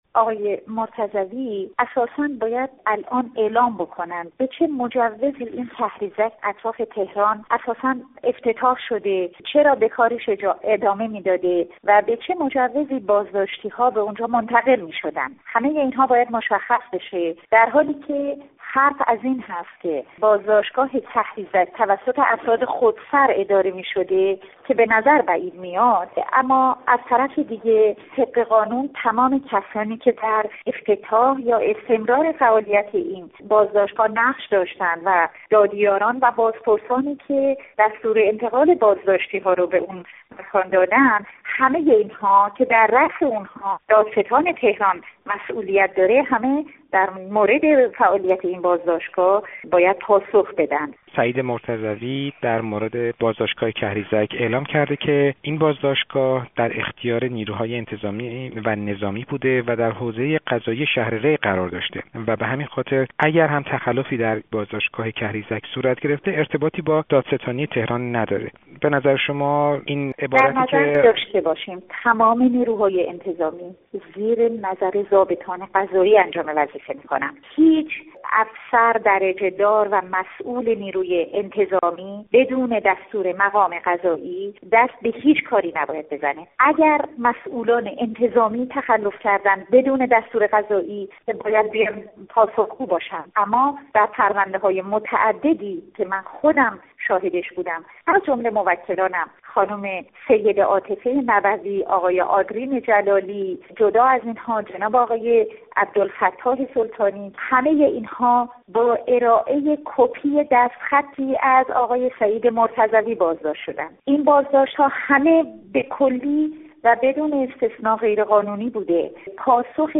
گفت‌وگو با نسرین ستوده، حقوقدان و فعال حقوق بشر در تهران